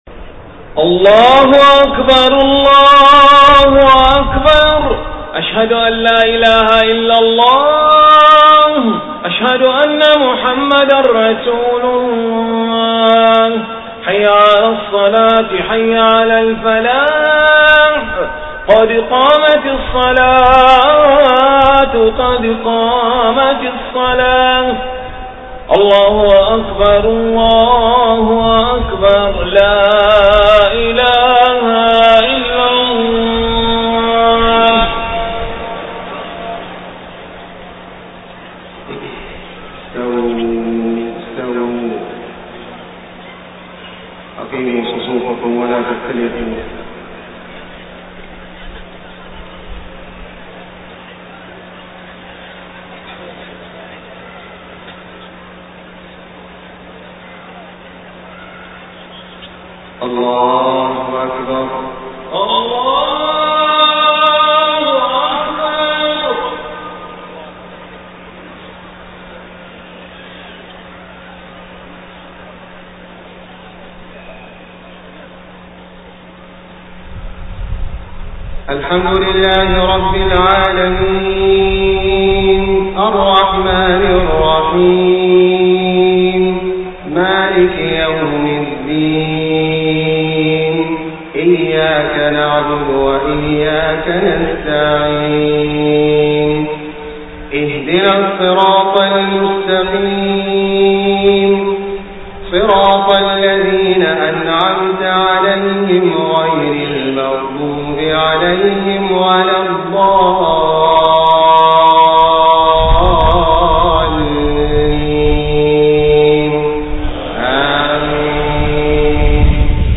صلاة العشاء 20 صفر 1431هـ سورة المنافقون كاملة > 1431 🕋 > الفروض - تلاوات الحرمين